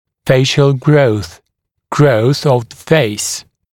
[‘feɪʃl grəuθ] [grəuθ əv ðə ‘feɪs][‘фэйшл гроус] [гроус ов зэ ‘фэйс]лицевой рост